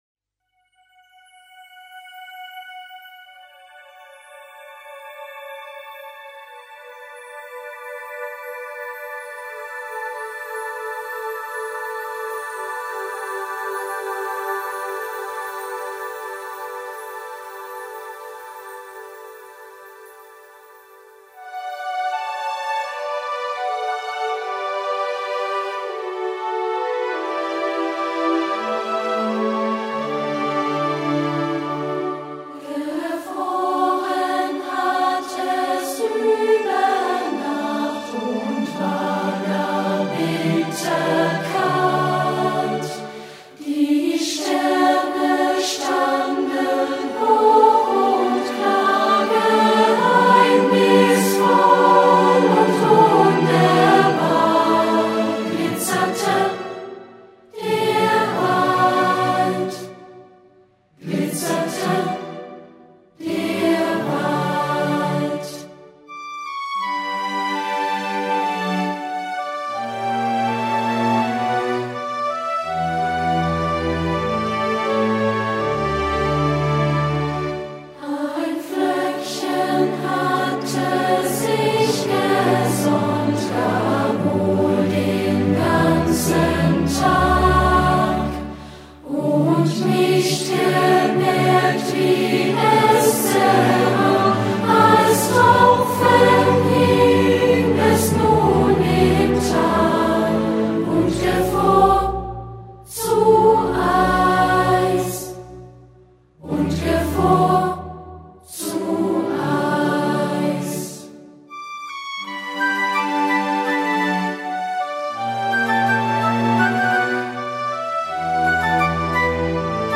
Gefroren hat es über Nacht – Playbackaufnahme